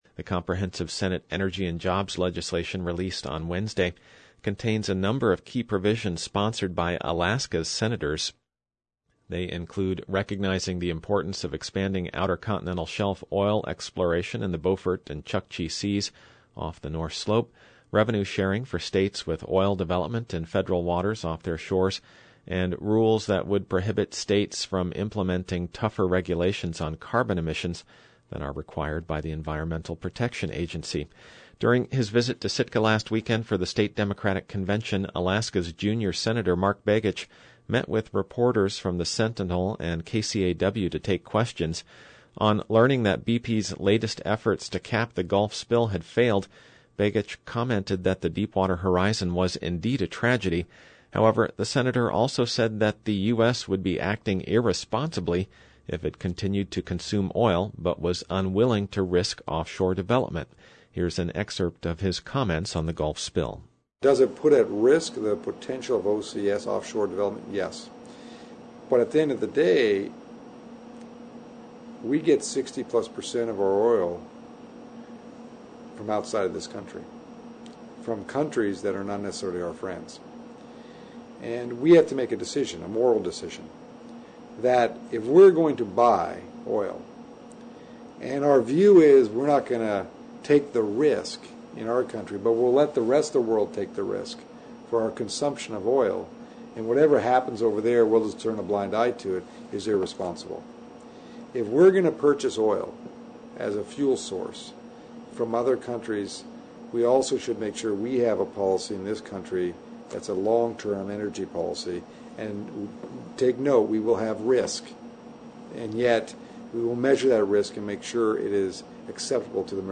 At a press conference in Sitka last weekend (5-8-10), Sen. Mark Begich commented on the effects the spill may have on development in the Outer Continental Shelf. He also addressed the Sealaska Lands selection bill.